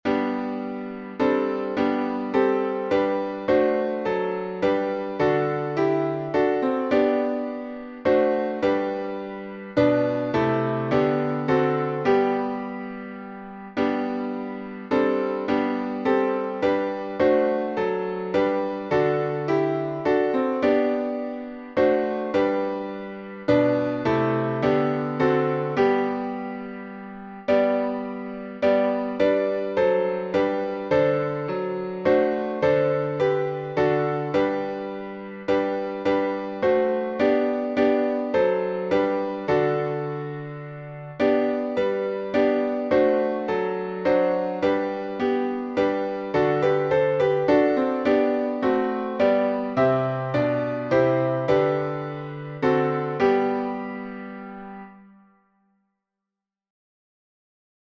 Key signature: G major (1 sharp)